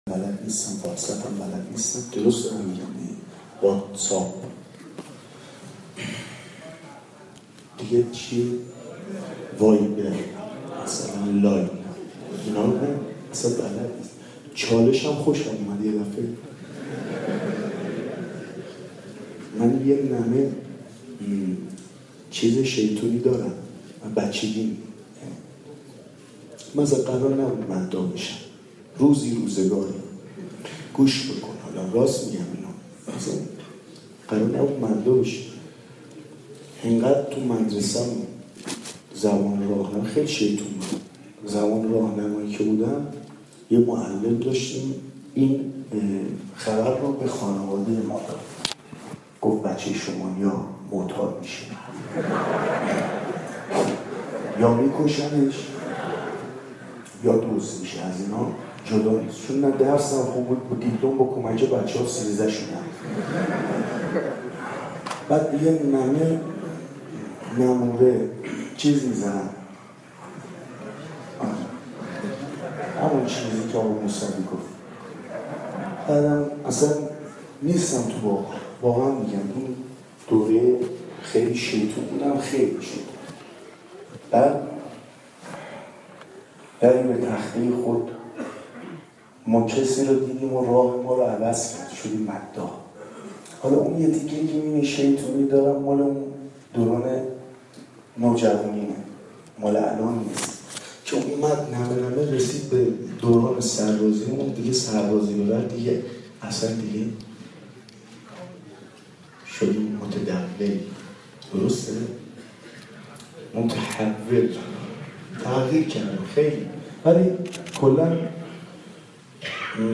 عقیق: مراسم هیئت هفتگی عشاق العباس ویژه سالگرد شهید شاطری چهارشنبه شب در مجتمع فرهنگی امام رضا(ع) برگزار شد.